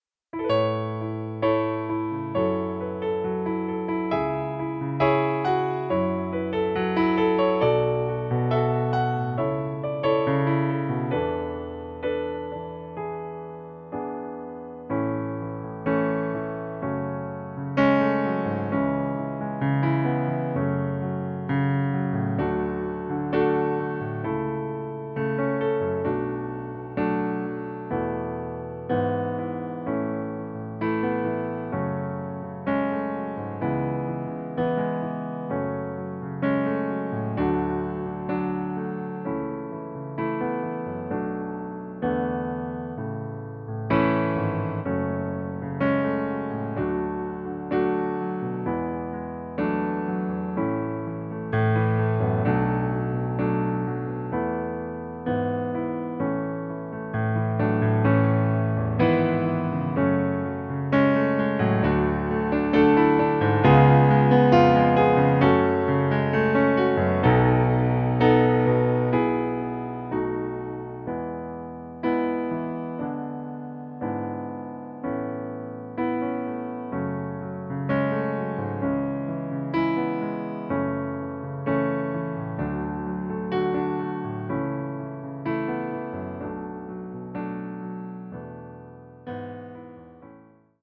Momentan verfügbar in A-Dur.